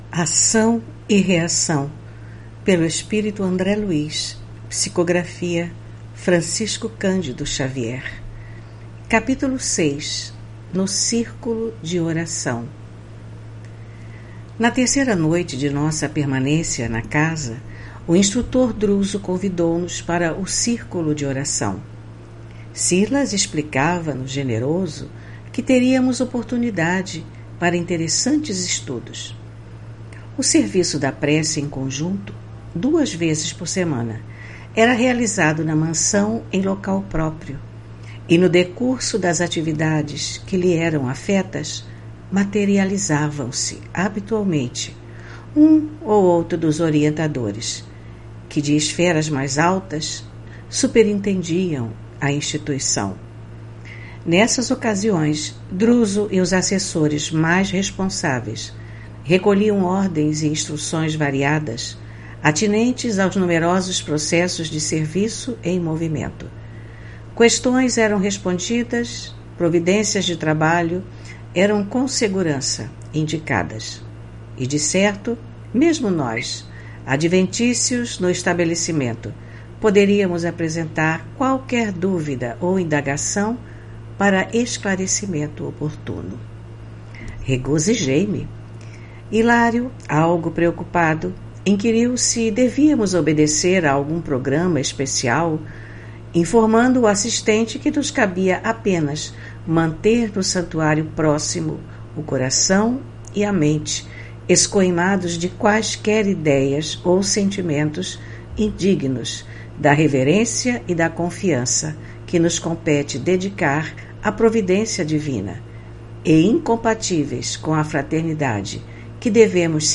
Leitura do livro: Ação e reação, autoria do espírito André Luiz, psicografia de Francisco Candido Xavier.